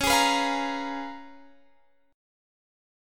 Listen to C#13 strummed